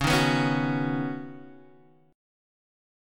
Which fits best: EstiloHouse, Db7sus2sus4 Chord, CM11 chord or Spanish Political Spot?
Db7sus2sus4 Chord